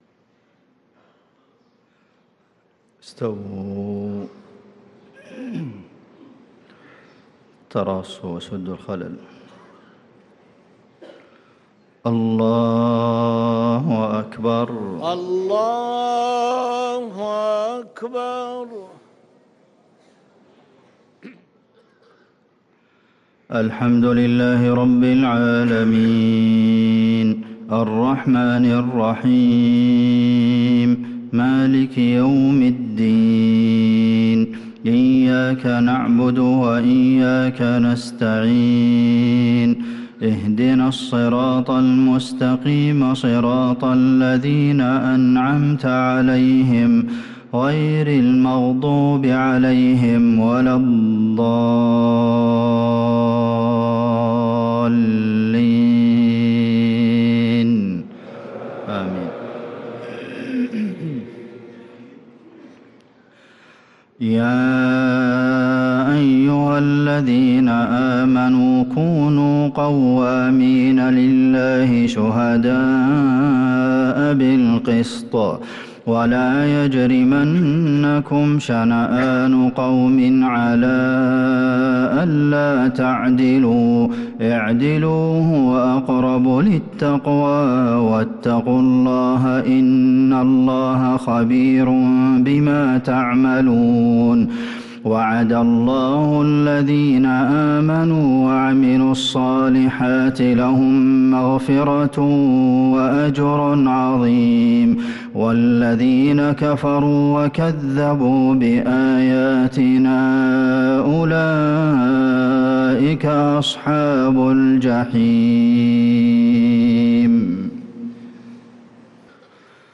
صلاة المغرب للقارئ عبدالمحسن القاسم 14 شعبان 1445 هـ
تِلَاوَات الْحَرَمَيْن .